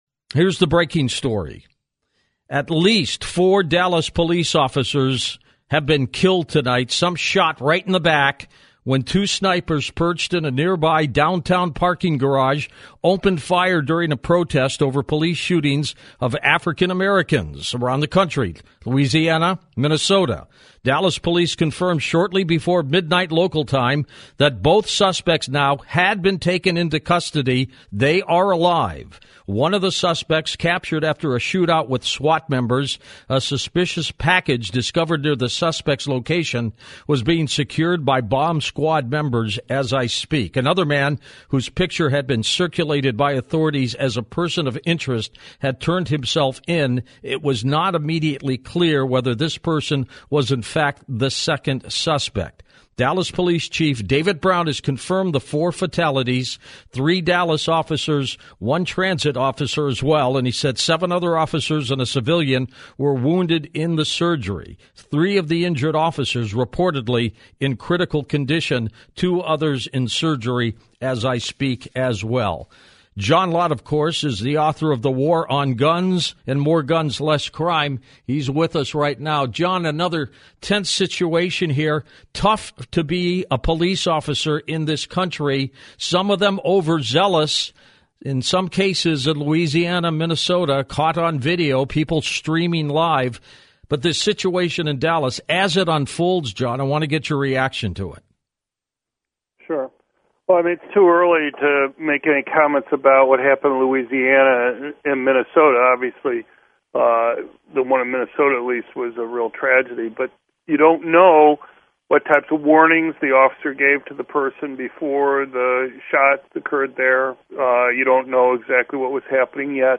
media appearance
Dr. John Lott talked to George Noory early Friday morning while America was still learning about the 5 police officers who were killed. Having watched the coverage of the shooting, John Lott told George Noory that he believed that the shooter had military training.